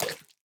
Minecraft Version Minecraft Version snapshot Latest Release | Latest Snapshot snapshot / assets / minecraft / sounds / mob / armadillo / eat3.ogg Compare With Compare With Latest Release | Latest Snapshot
eat3.ogg